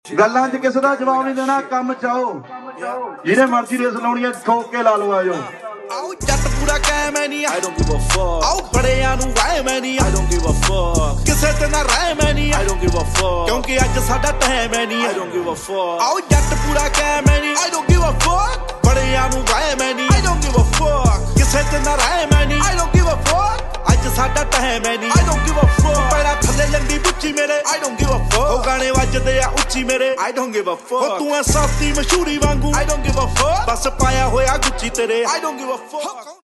Holden commodore Sv6 sound system upgrade to speakers and Tweeter. running through Amp